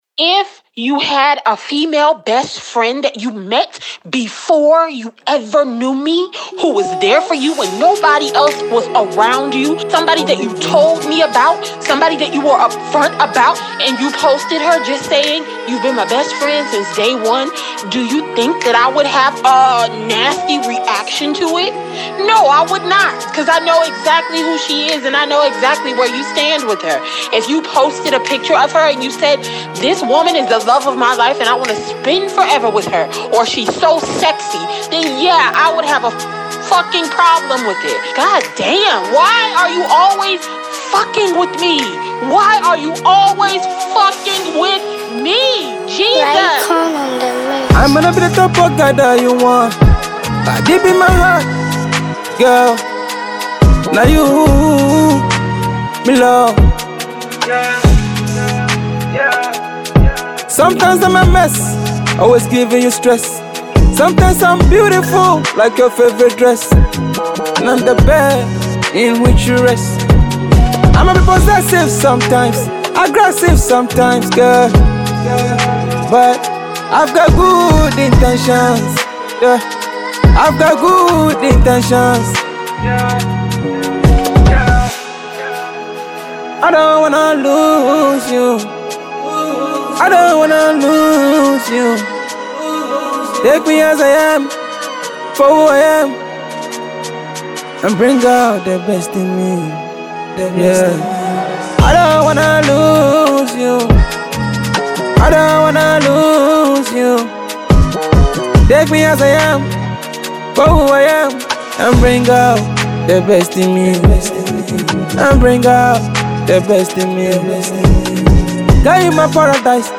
Afro-pop